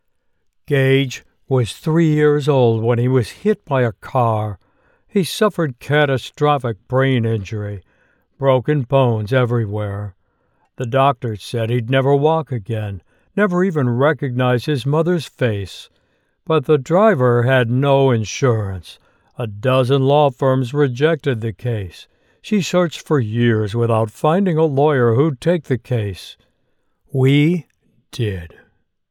Law Firm Ad
English - Midwestern U.S. English
Middle Aged
My very quiet home studio is equipped with a Neumann TLM 103 microphone and other professional gear with high speed internet for smooth audio delivery!